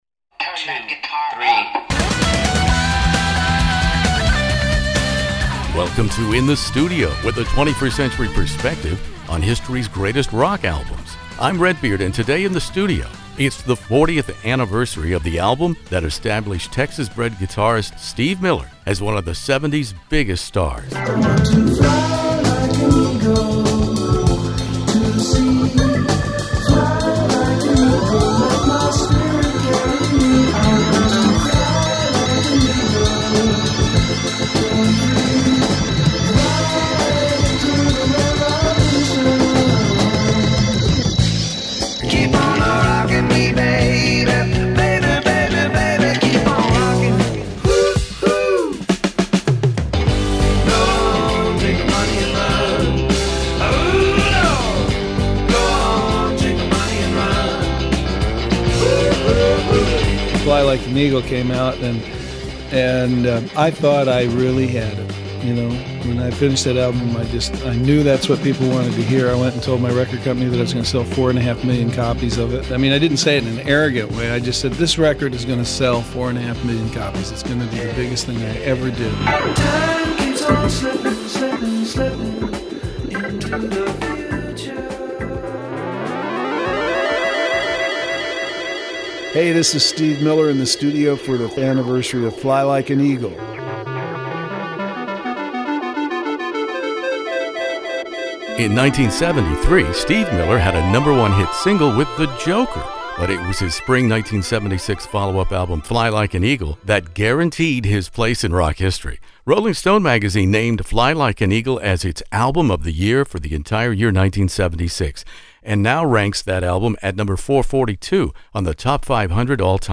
Steve Miller Fly Like an Eagle interview In the Studio